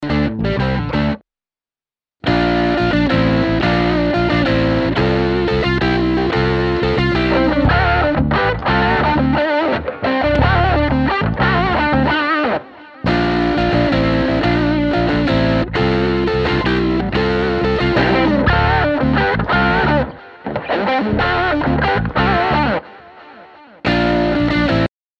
I didn't use any widening for the hard left ad right examples, just the same cutoffs.
Hard left and right nothing on it